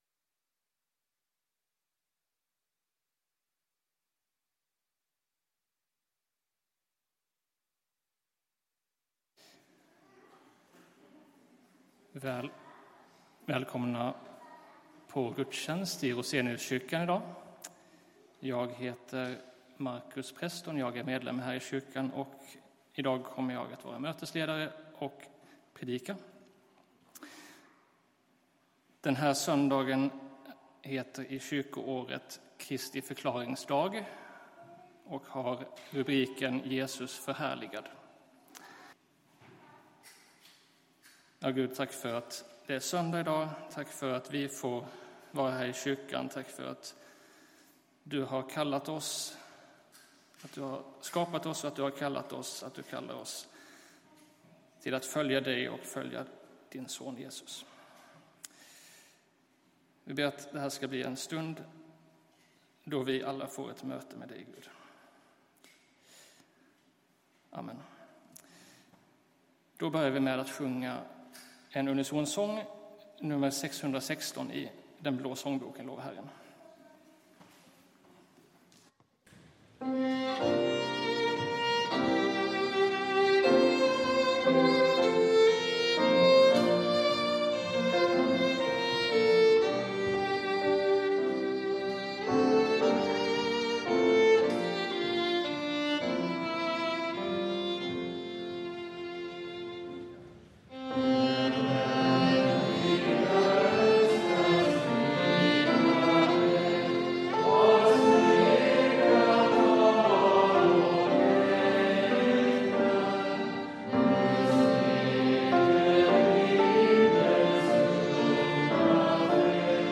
Gudstjänst